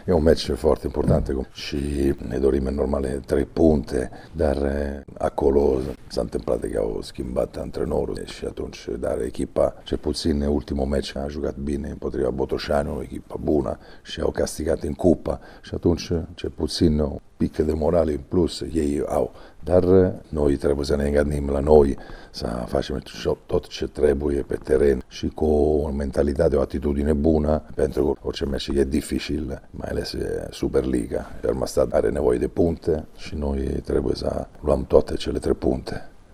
Înaintea acestui duel, antrenorul “șepcilor roșii”, italianul Cristiano Bergodi, a declarat că singurul obiectiv în acest joc este victoria.